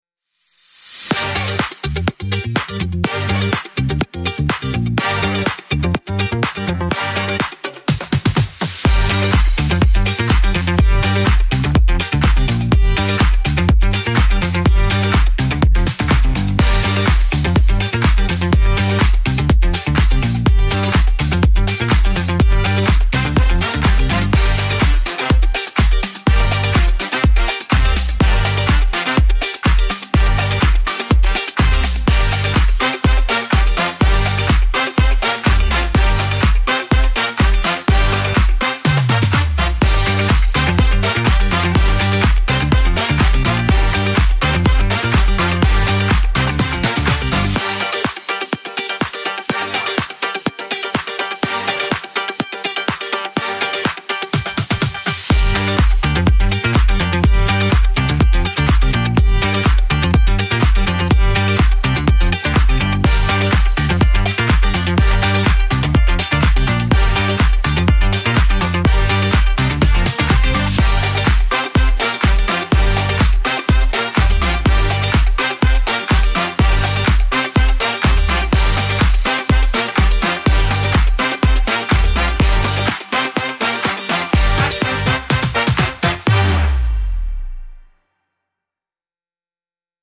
high-energy pop instrumental with funky rhythms and brass stabs